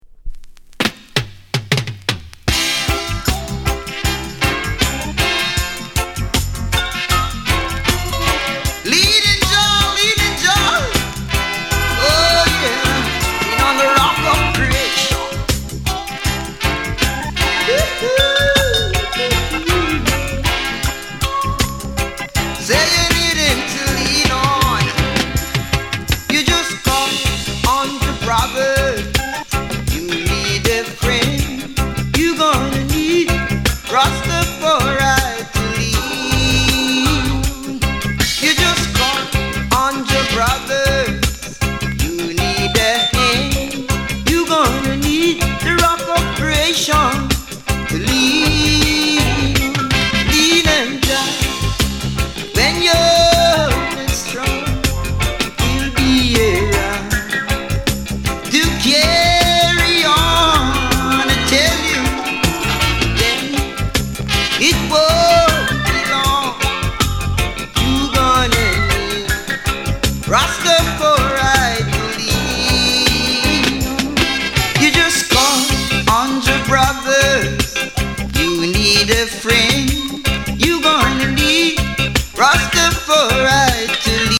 Genre: Reggae / Roots